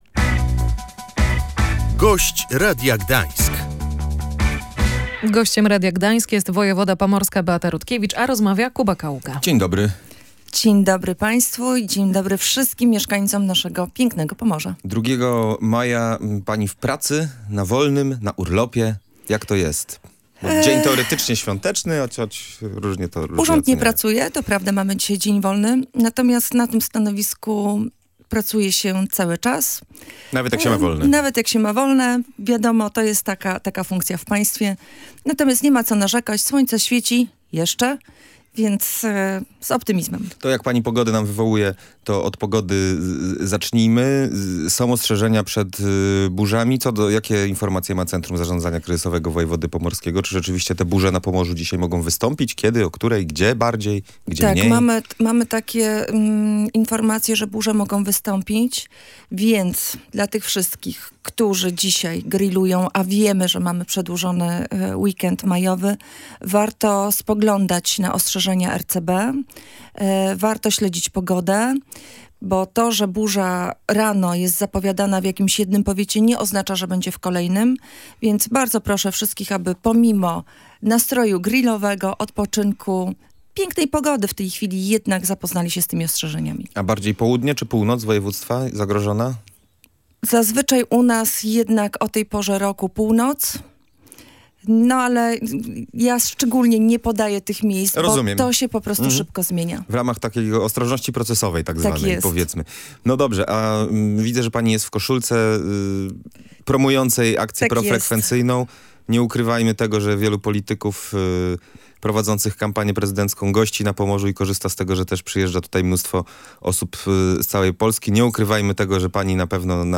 Akademia Marynarki Wojennej przeprowadzi szkolenia samorządowców w zakresie bezpieczeństwa – zapowiada wojewoda pomorska Beata Rutkiewicz. Jak mówiła w Radiu Gdańsk, sama na początku tygodnia przeszła trzydniowy Wyższy Kurs Ochrony Ludności.